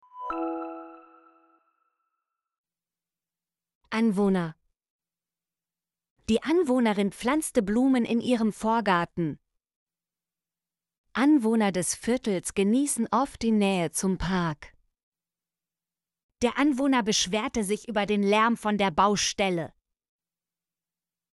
anwohner - Example Sentences & Pronunciation, German Frequency List